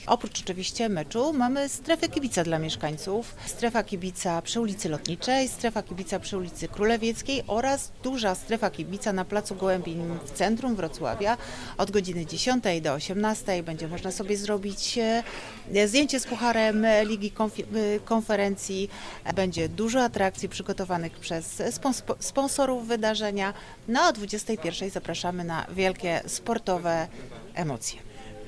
Wiceprezydent mówi co jeszcze będzie się działo we Wrocławiu oprócz meczu.